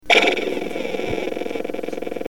Category: Sound FX   Right: Personal
Tags: Photon Sounds Photon Sound Photon clips Sci-fi Sound effects